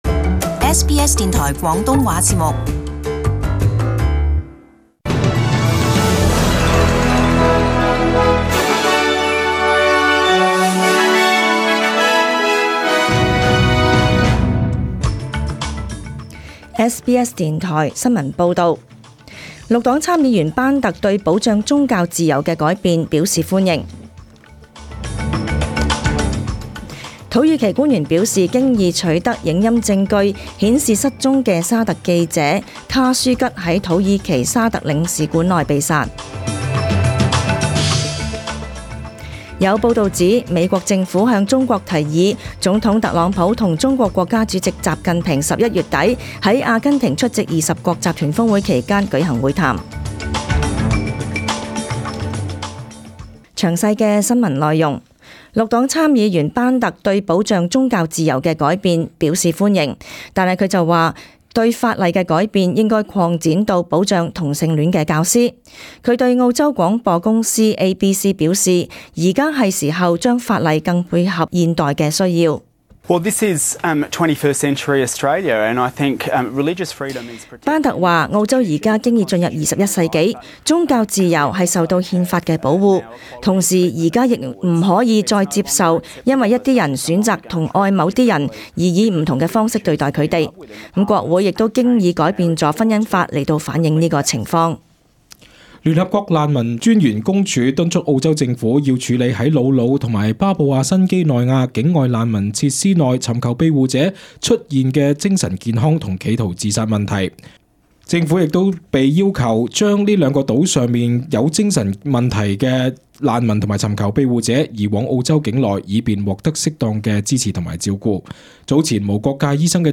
SBS Cantonese 10am news Source: SBS